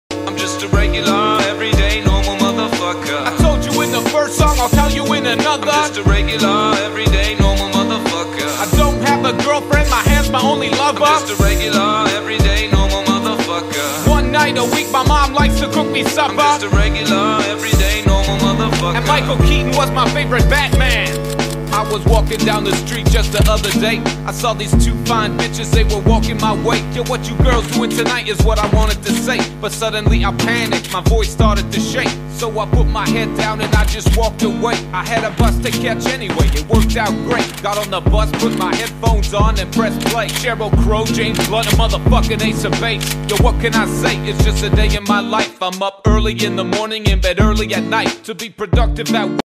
Review M1873 Lever Action Rifle